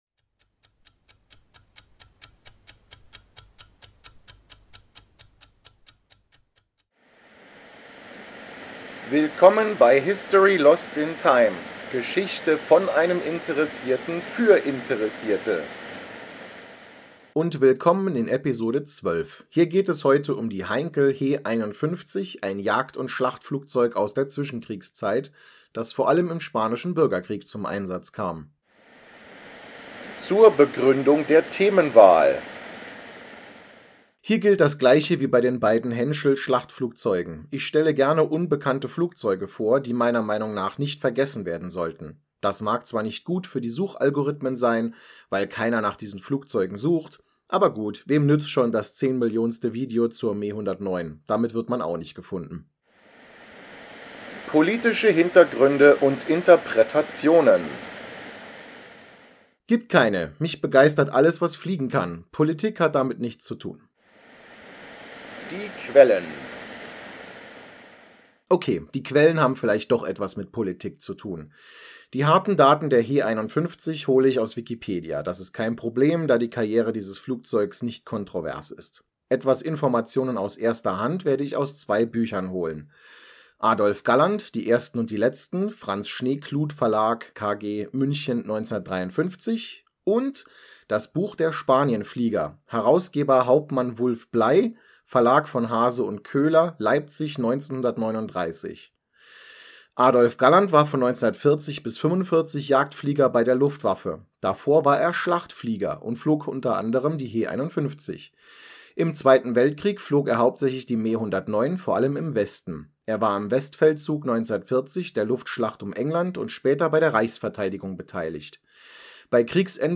Sie diente zunächst als Jäger, dann aber als Schlachtflugzeug und das vorallem im Spanischen Bürgerkrieg. Im zweiten Weltkrieg wurde sie hauptsächlich als Jagdflugzeug-Trainer eingesetzt. In dieser Episode möchte ich die Geschichte der Heinkel He 51 mit Stimmen von Piloten aus dem Spanischen Bürgerkrieg wie z.B. Adolf Galland besprechen.